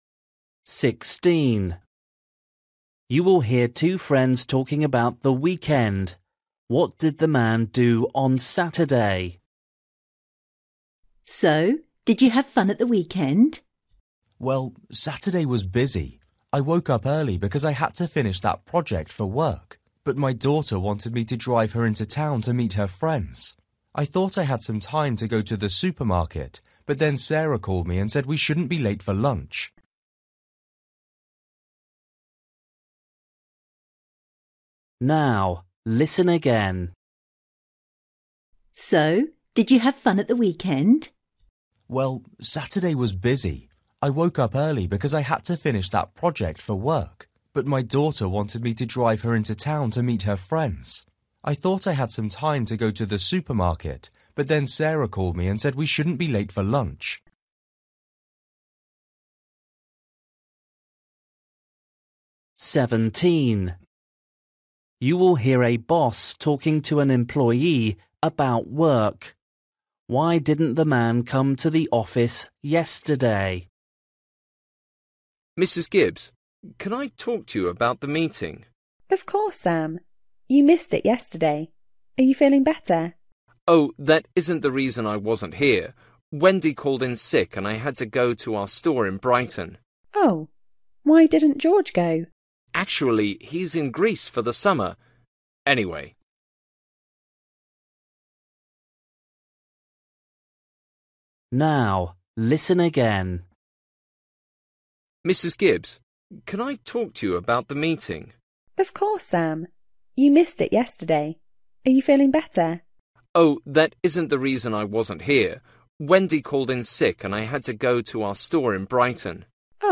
Listening: everyday short conversations
16   You will hear two friends talking about the weekend.
17   You will hear a boss talking to an employee about work.
18   You will hear a woman talking about sailing.